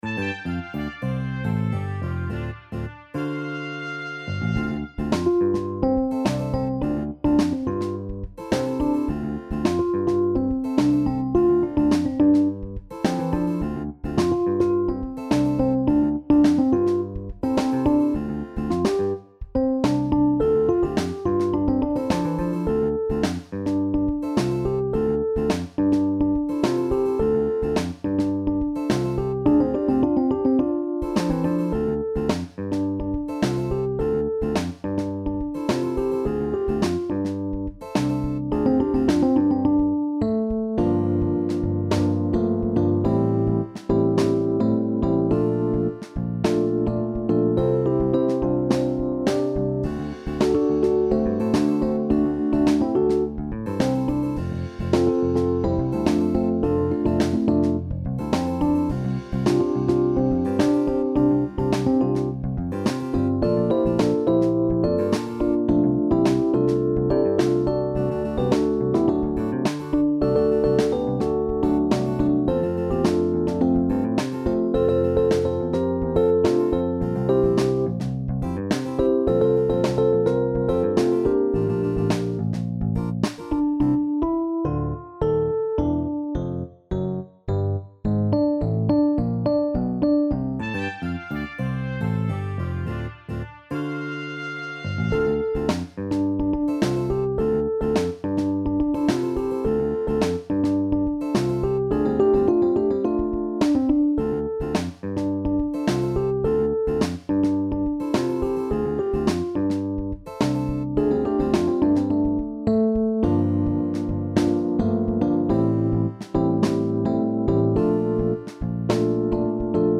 SSATB met piano of band